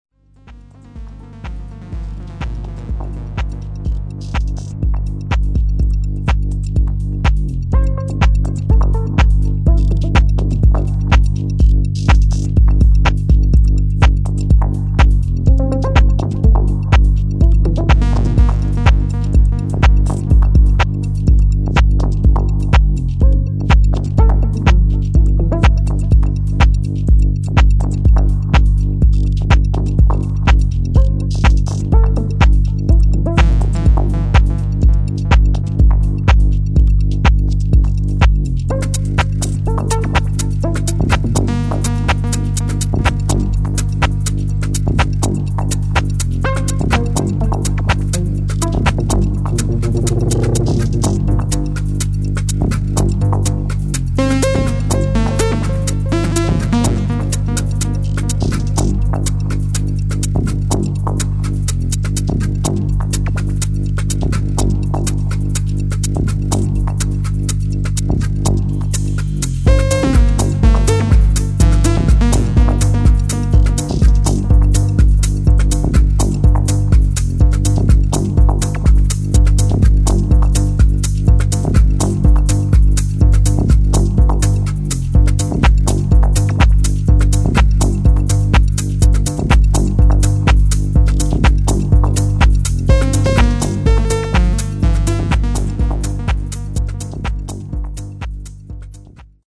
[ HOUSE | TECHNO | MINIMAL ]